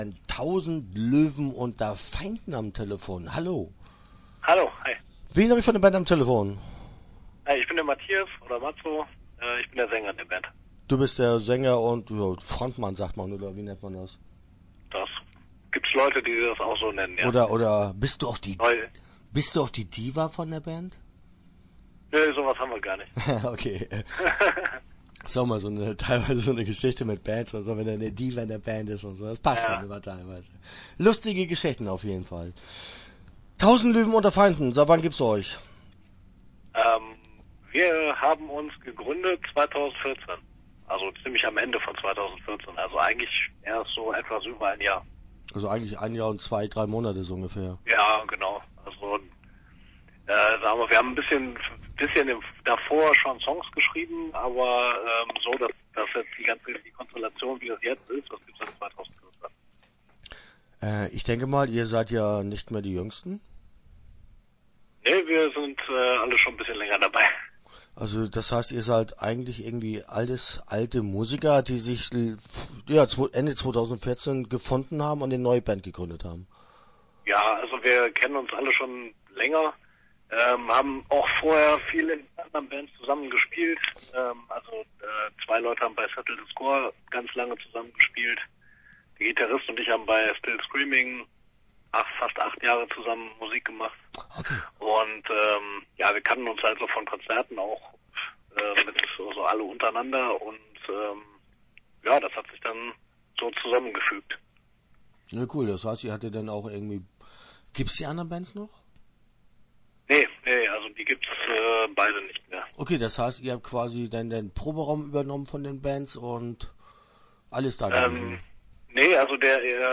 TLuF - Interview Teil 1 (12:00)